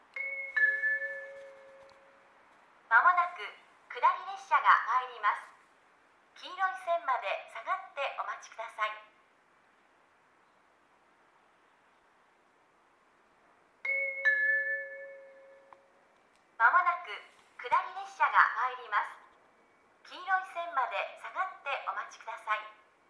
この駅では接近放送が設置されています。
１番線仙石線
接近放送普通　石巻行き接近放送です。